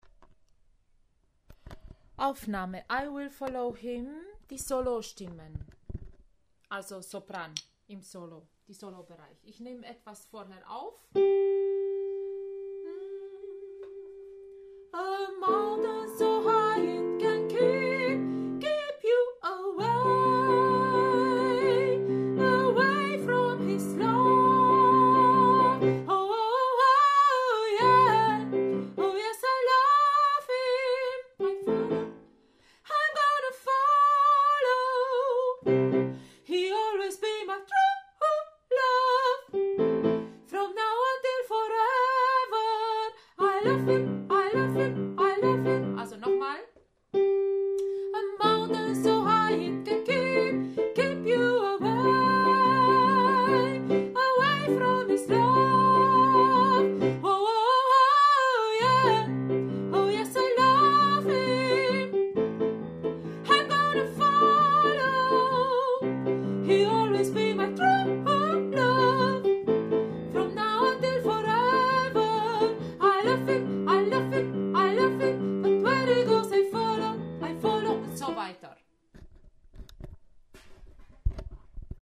I will follow him – Solo Sopran